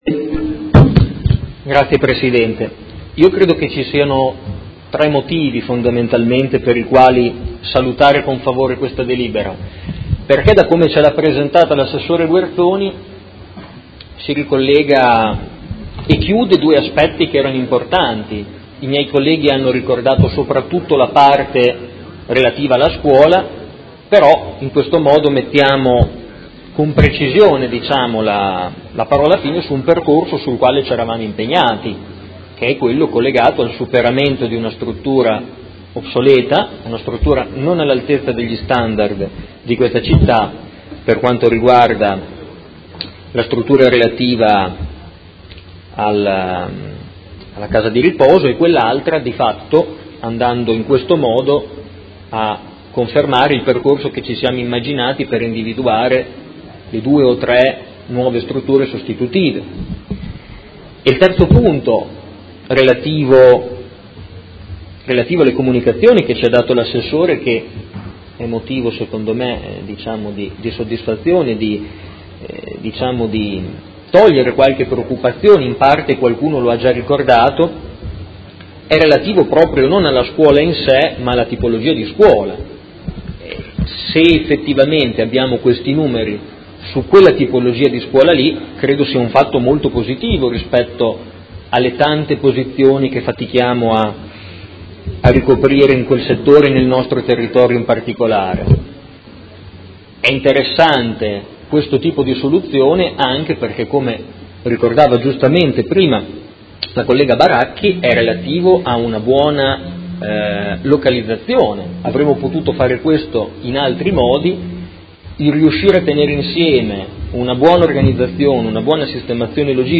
Seduta del 31/05/2018 Dibattito.
Audio Consiglio Comunale